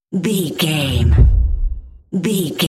Dramatic hit very deep trailer
Sound Effects
Atonal
heavy
intense
dark
aggressive